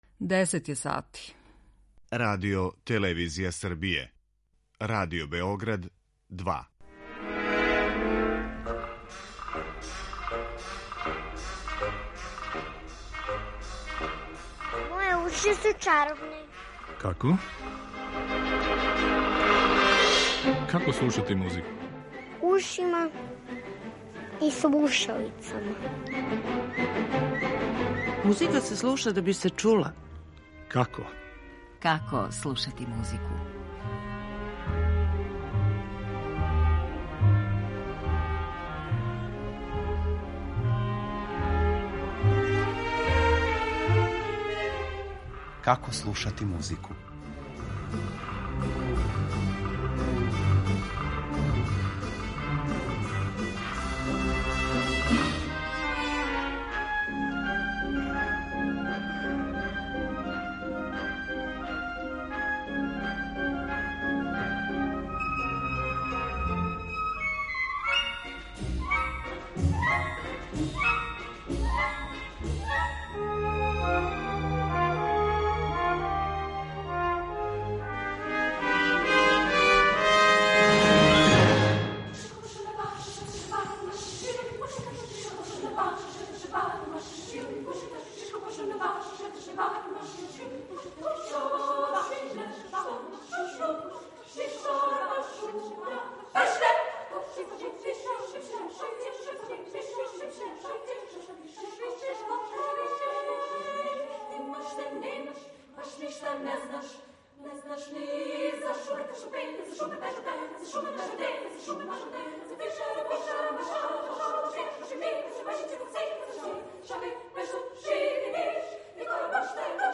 Одабрани музички примери потичу из различитих епоха и музичких жанрова - од ренесансе до савремене музике, односно од соло-песме преко хорске музике до вокално-инструменталног жанра. Видећемо како се комични садржај текстова композиција илуструје у делима Монтевердија, Бабића, Орфа, Пуленка, Прокофјева и других.